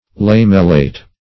Lamellate \Lam"el*late\, Lamellated \Lam"el*la`ted\, a. [See